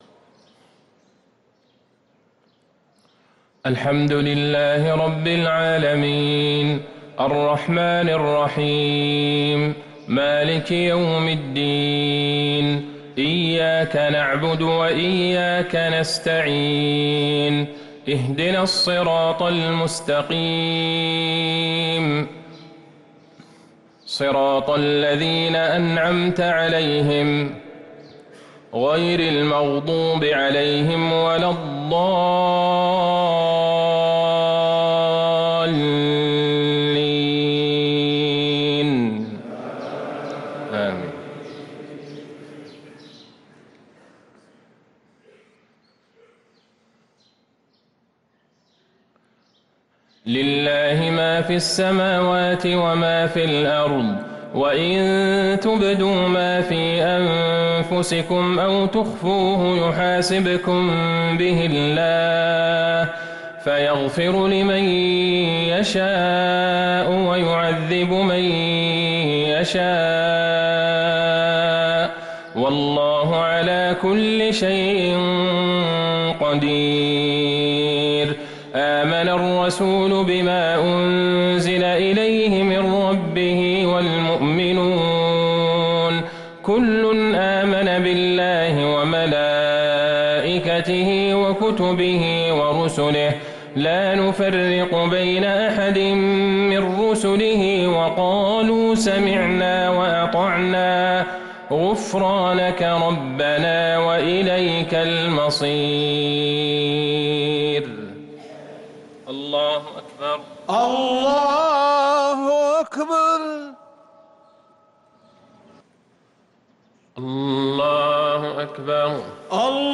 صلاة المغرب للقارئ عبدالله البعيجان 15 شعبان 1444 هـ
تِلَاوَات الْحَرَمَيْن .